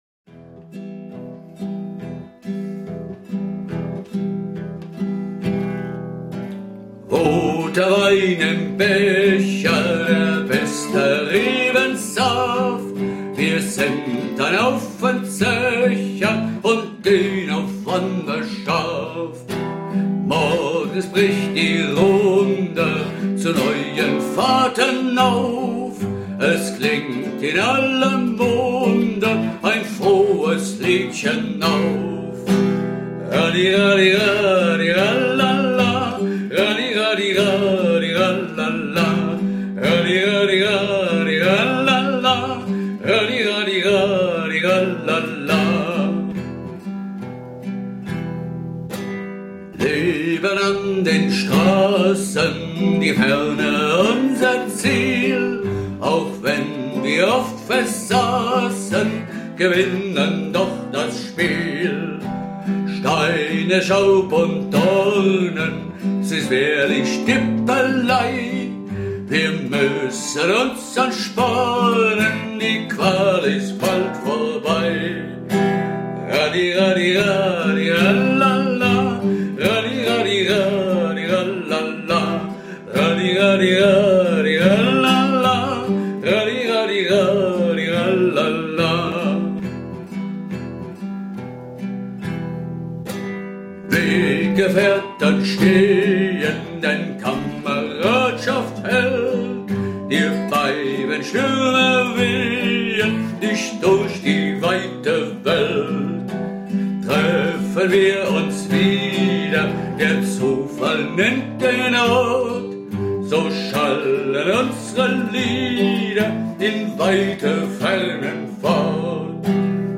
Ort: Diez (Studio)
>>> Hörprobe : MIDI von 2000 [8.874 KB] - mp3